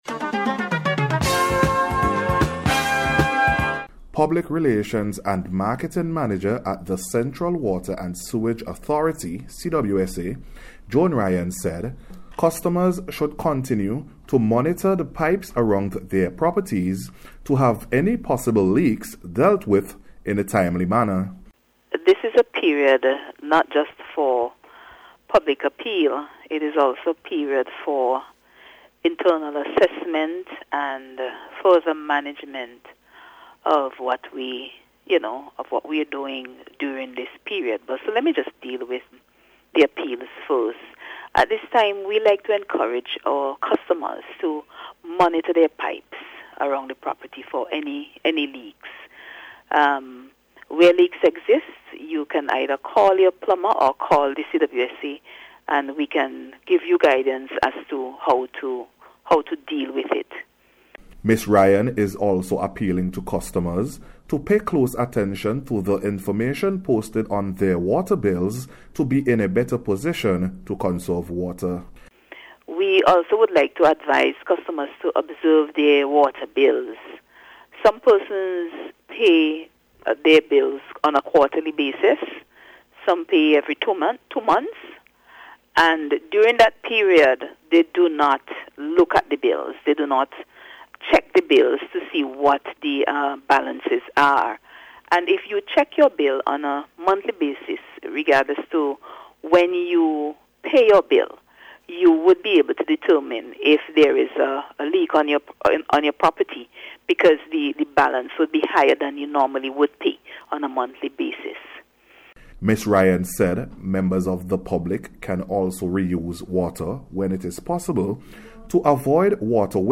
NBC’s Special Report – Monday January 30th 2023